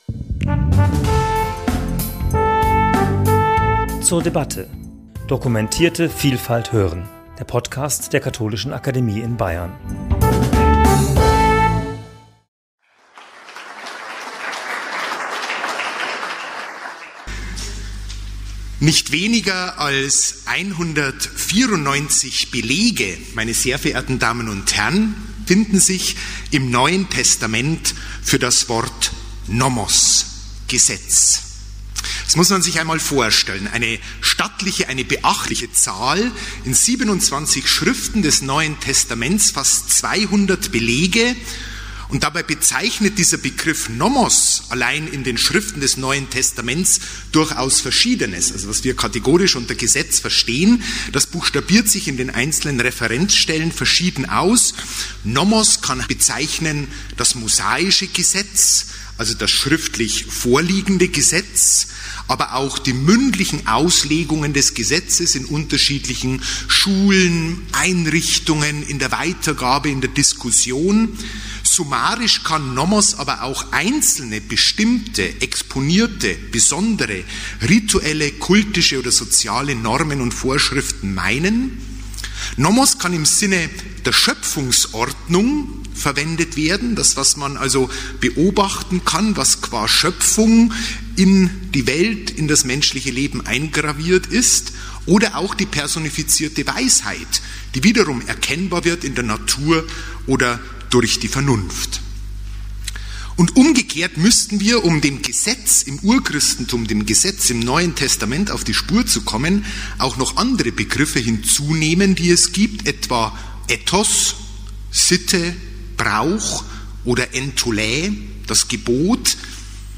Diese Gemengelage bildet das Fundament unserer Jubiläumstagung 'Alles was Recht ist', mit der unsere Kooperation mit dem Katholischen Bibelwerk ihre Fortsetzung findet.